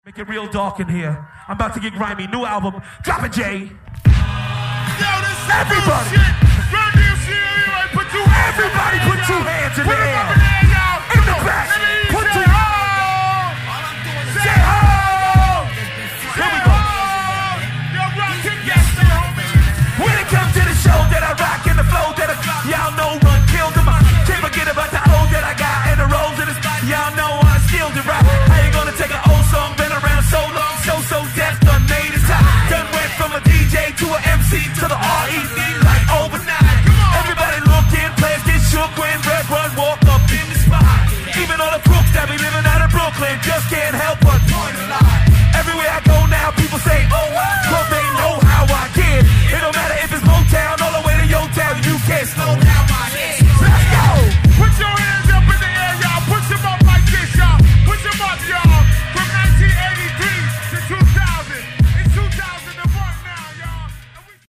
Live at the House of Blues